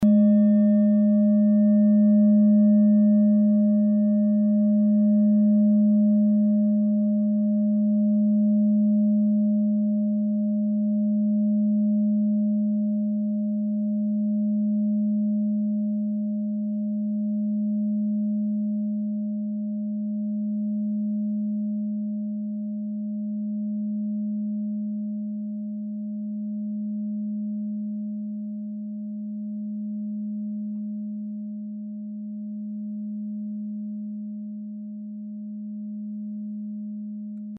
Planetentonschale: Mondton
Diese Klangschale ist eine Handarbeit aus Bengalen. Sie ist neu und wurde gezielt nach altem 7-Metalle-Rezept in Handarbeit gezogen und gehämmert.
Hörprobe der Klangschale
Filzklöppel oder Gummikernschlegel
Oktaviert man diese Frequenz 29 mal, hört man die Mondumlaufsfrequenz bei 210,42 Hz.
Auf unserer Tonleiter entspricht er etwa dem "Gis".
klangschale-ladakh-13.mp3